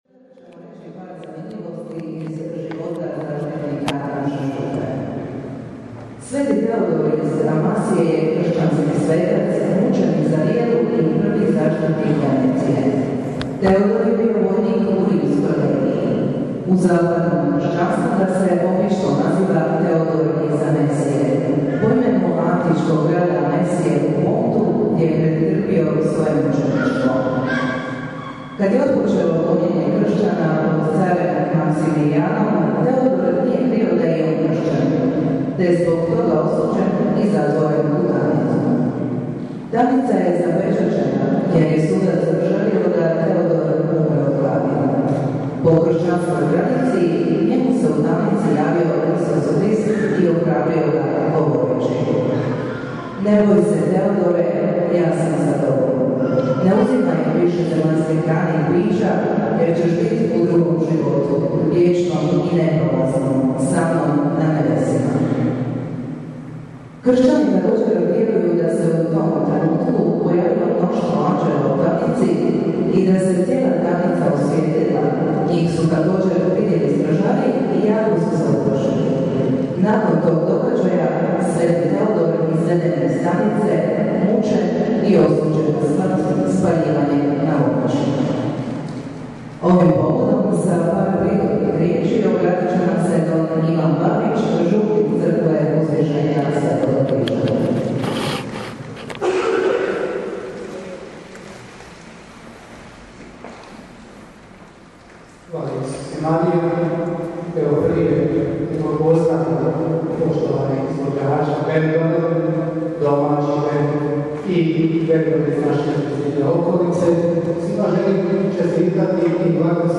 U župnoj crkvi Uzvišenja sv. Križa u Okrugu Gornjem, u subotu 4. studenog održan je u povodu obilježavanja župnog blagdana sv. Tudora i Dana općine Okrug koncert pod nazivom „Mladi sv. Tudoru“.
UVODNA RIJEČ I NAJAVE: